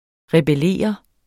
rebellere verbum Bøjning -r, -de, -t Udtale [ ʁεbəˈleˀʌ ] Betydninger sætte sig op imod nogen; gøre oprør grammatik NOGEN rebellerer (mod NOGEN / NOGET ) Karl Marx opfordrede arbejderne i alle lande til at forene sig.